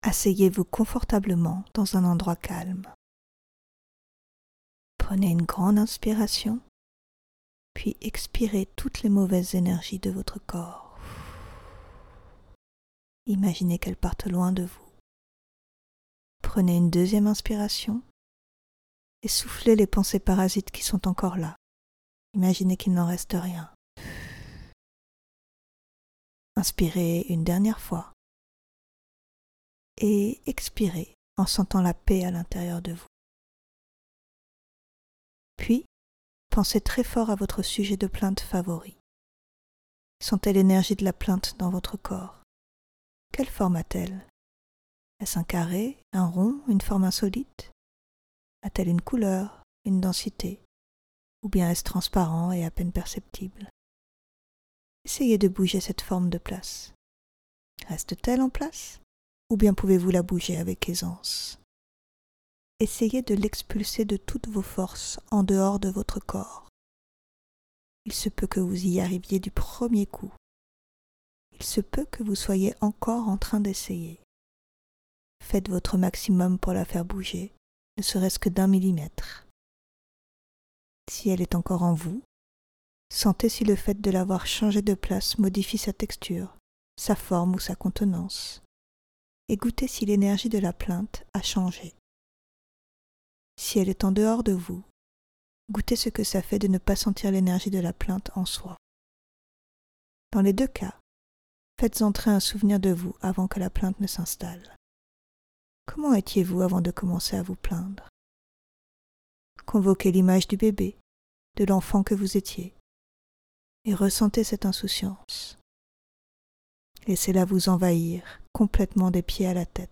Découvrez notre méditation guidée gratuite pour vous aider à arrêter de vous plaindre et à cultiver une attitude positive.